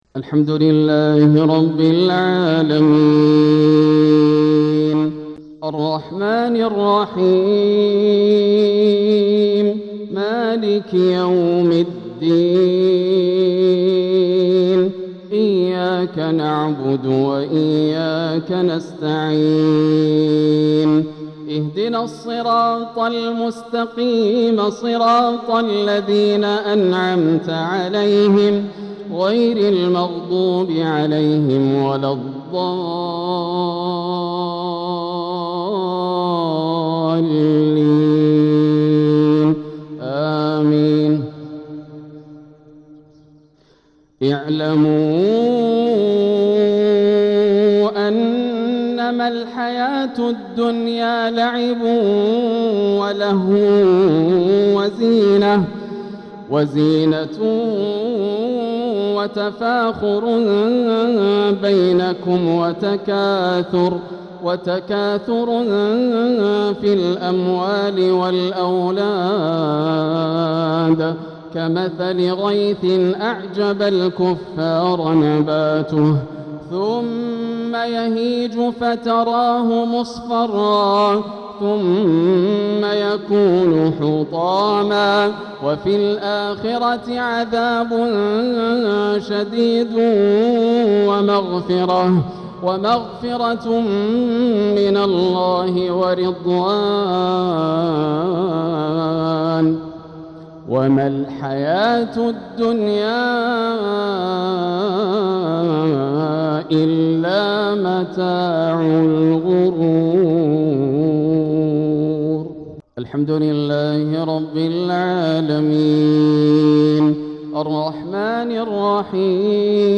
صلاة الجمعة 7 صفر 1447هـ من سورة الحديد 20-21 | Jumu’ah prayer from Surah Al-Hadeed 1-8-2025 > 1447 🕋 > الفروض - تلاوات الحرمين